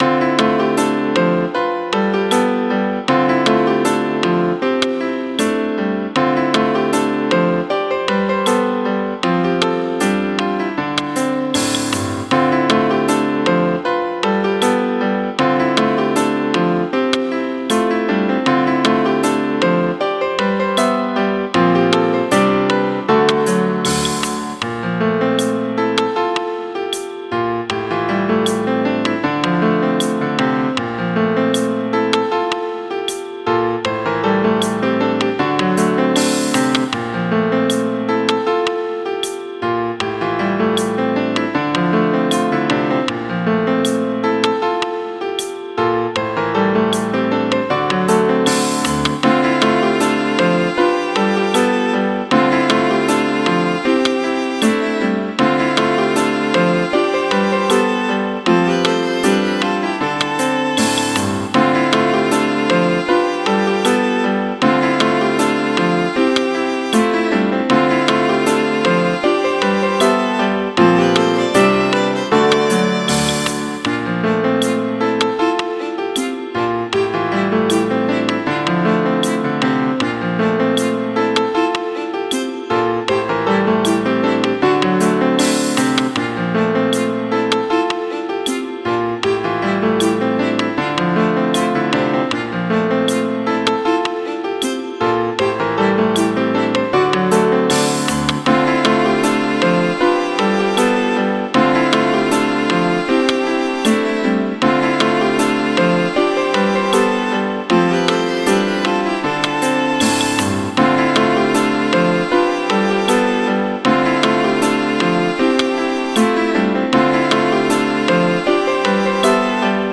イメージ：ハーブ採集　ジャンル：ケルト音楽、異世界
コメント：ケルト系、スローテンポの曲です。フィールド曲で、癒しも兼ねています。